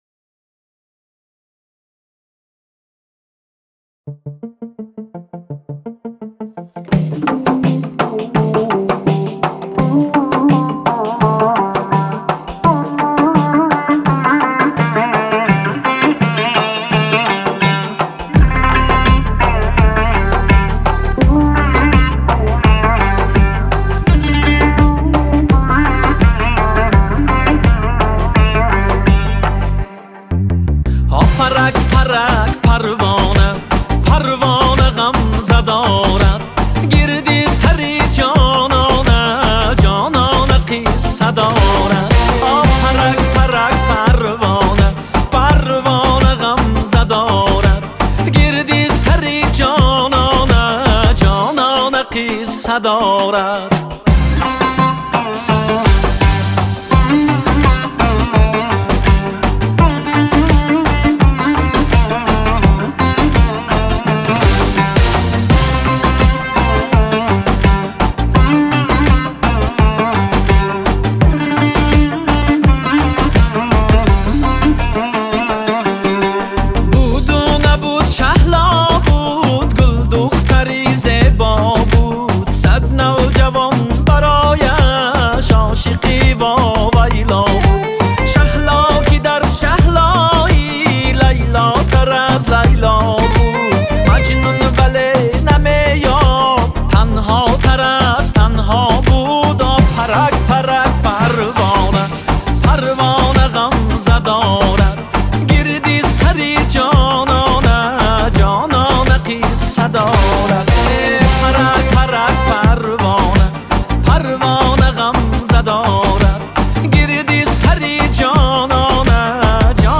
Категория: Эстрада